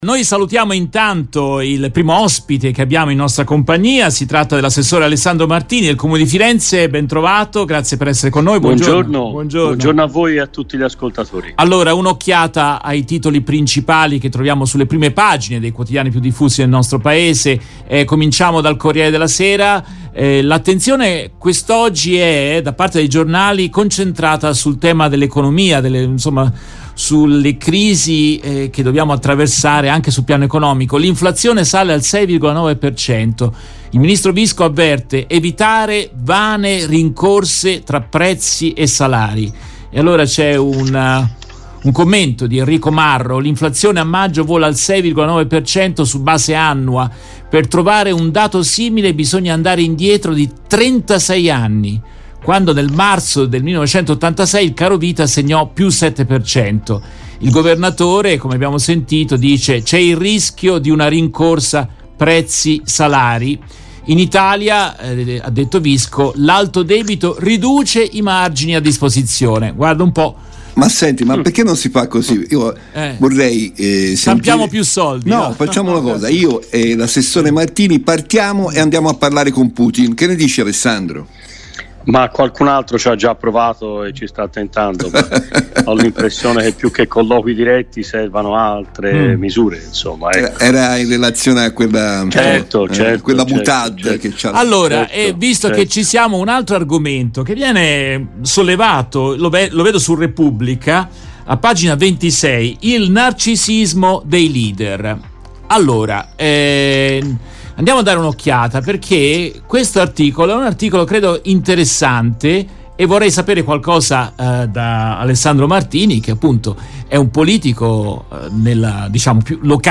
In questa intervista tratta dalla diretta RVS del 1 giugno 2022, ascoltiamo l'assessore al Comune di Firenze Alessandro Martini.